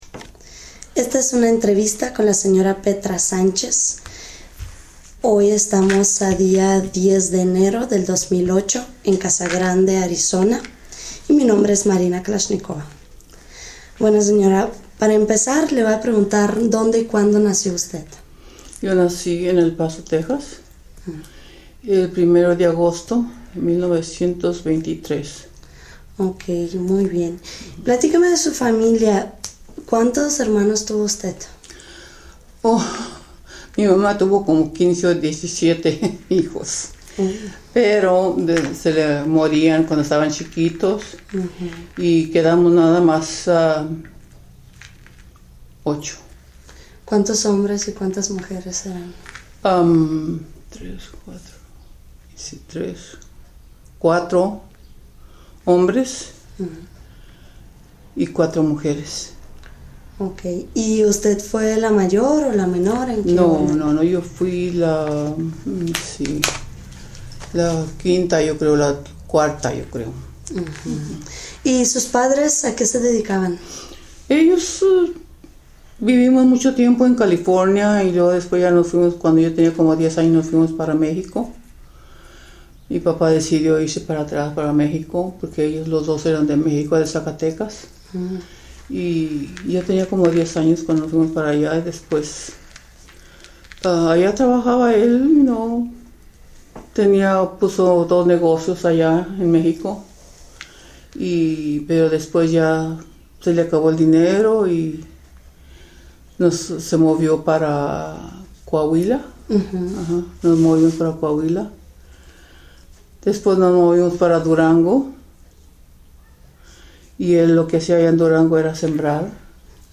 Location Casa Grande, Arizona